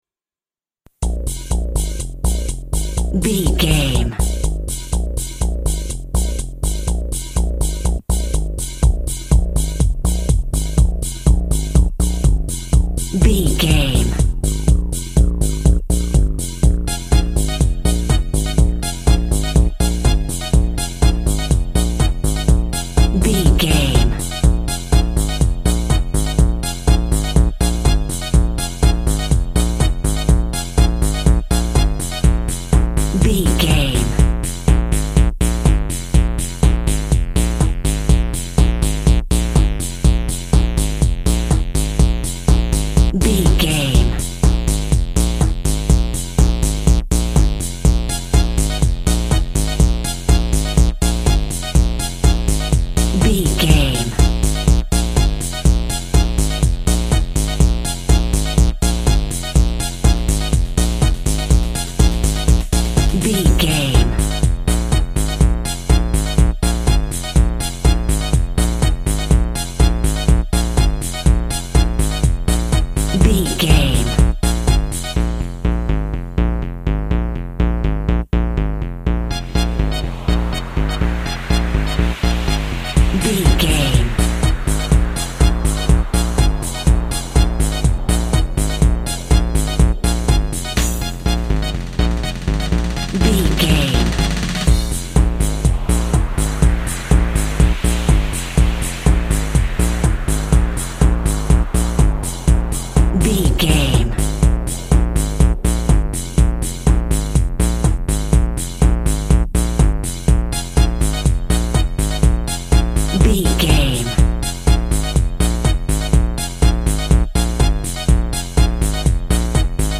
Aeolian/Minor
B♭
uplifting
futuristic
hypnotic
industrial
groovy
driving
drums
synthesiser
house music
energetic
synth lead
synth bass